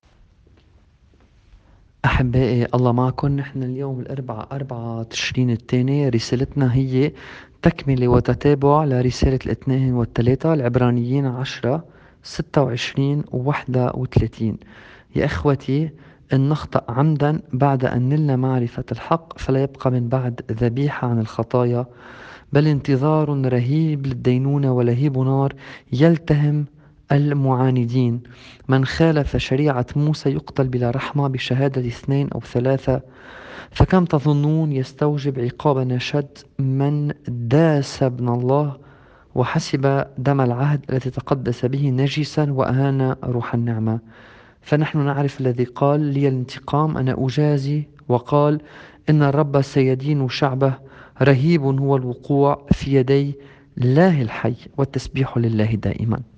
الرسالة بحسب التقويم الماروني :